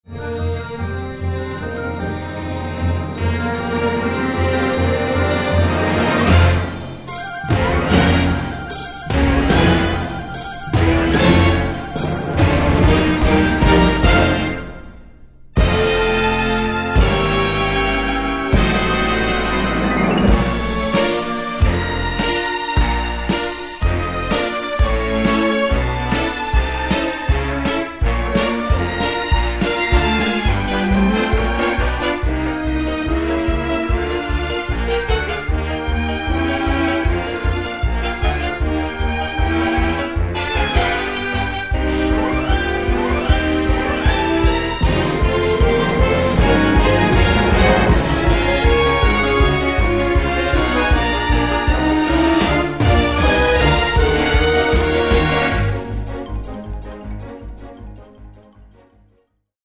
Film , Pop , Musical
Karaoke , Instrumental
backing track